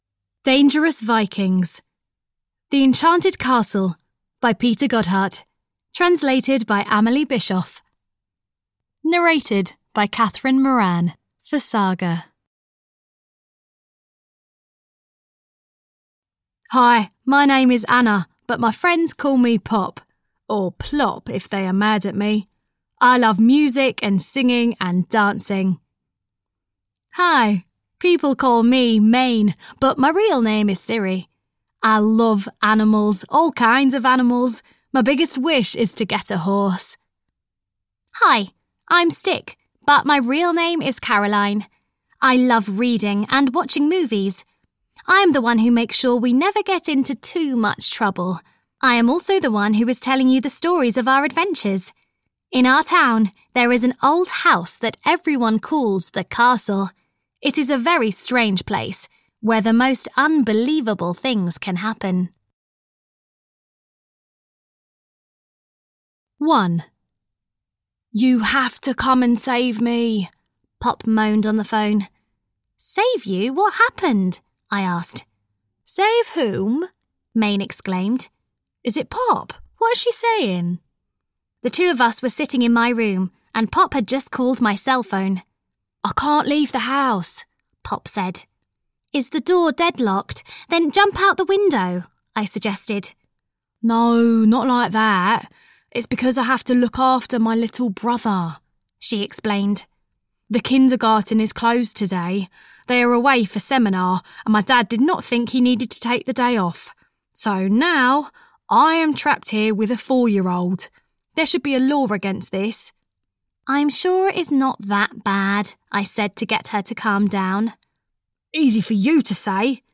The Enchanted Castle 7 - Dangerous Vikings (EN) audiokniha
Ukázka z knihy